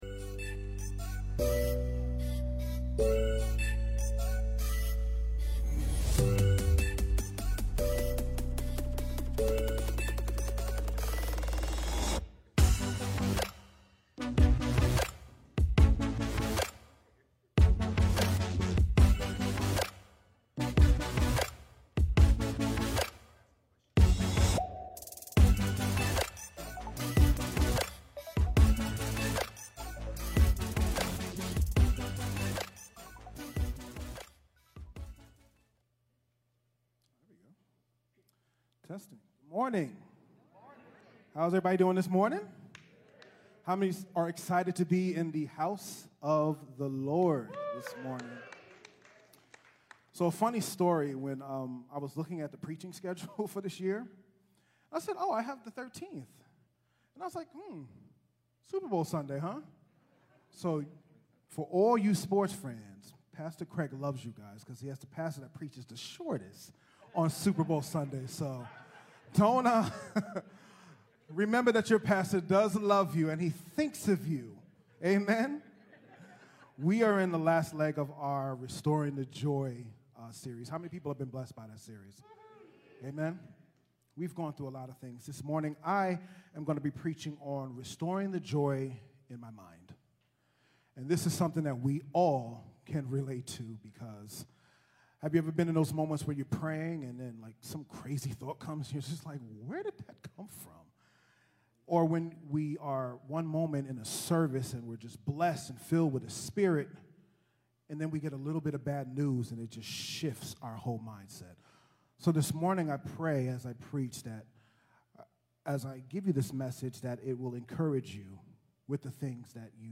CTK-Sermon-2.mp3